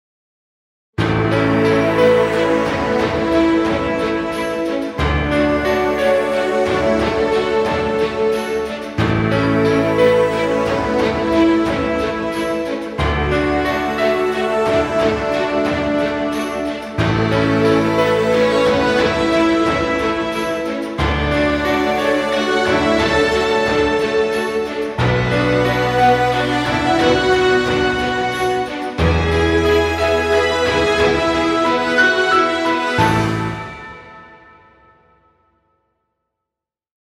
Epic music, exciting intro, or battle scenes.
Cinematic dramatic music. Trailer music.